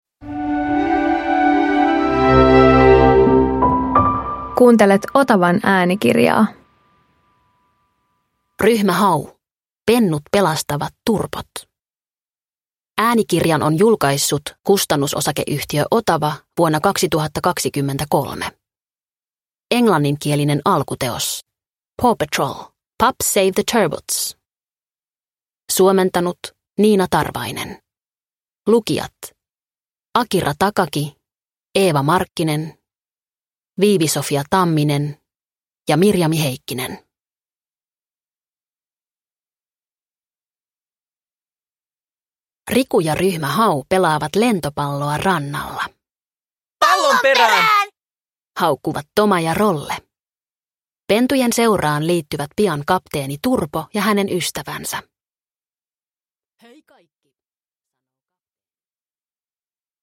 Ryhmä Hau - Pennut pelastavat Turpot – Ljudbok – Laddas ner